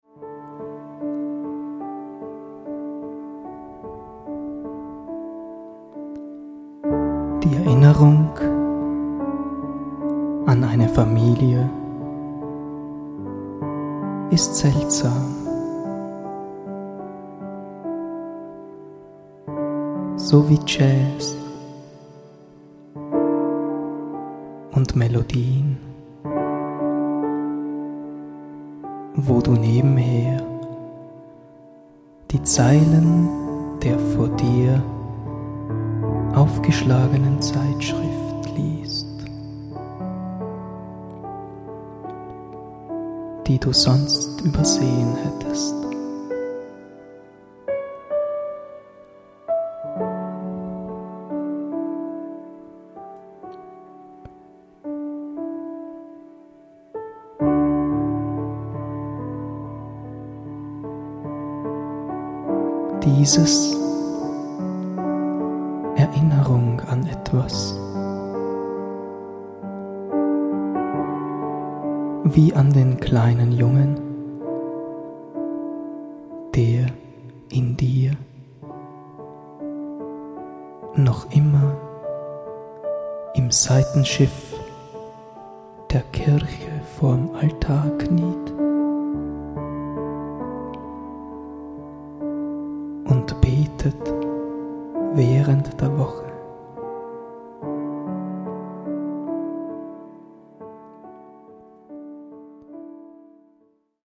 Eine musikalische Dichterlesung
poetische Bilderreise
Seine wohltuende weiche und warmherzige Stimme
E-Piano